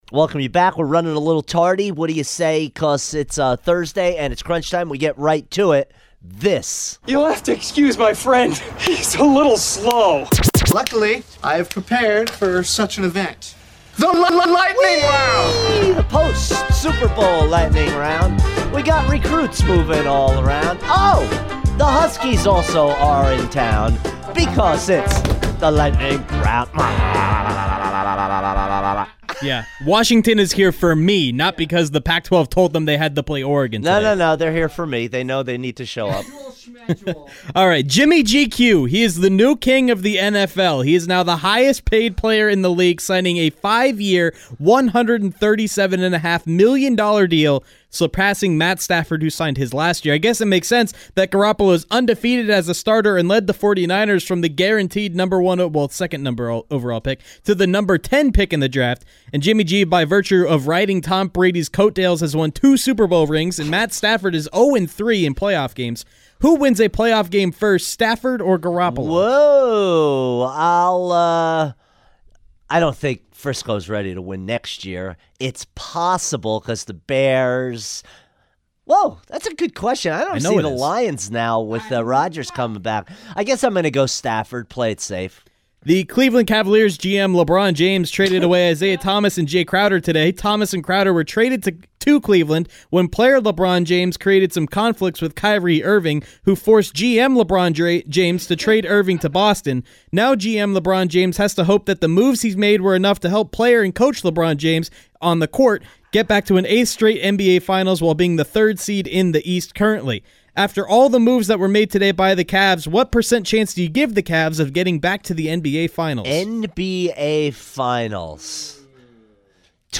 rapid-fire style